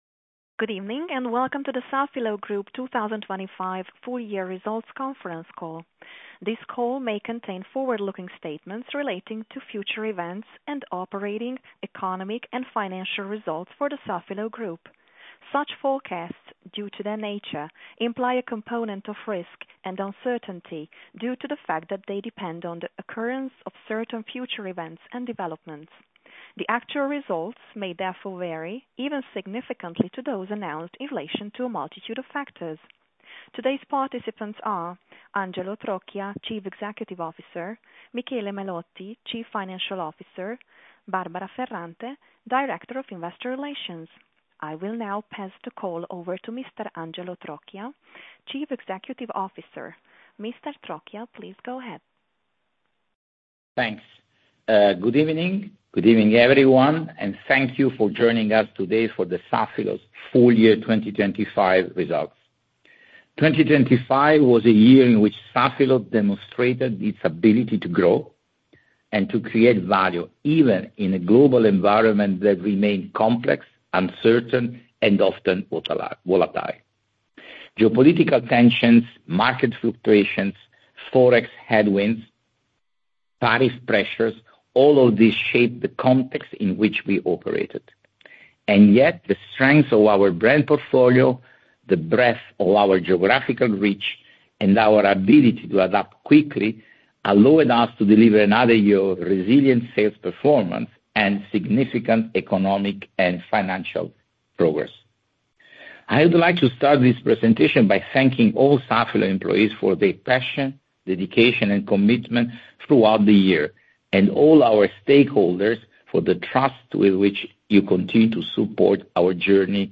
FY 2025 RESULTS 17.03.2026 14:01 Press release 187.3 kB 12.03.2026 16:41 Press release Price Sensitive 563.0 kB 12.03.2026 Presentation 2.5 MB 12.03.2026 Conference call 9.8 MB 12.03.2026 Audio Webcast 96.5 MB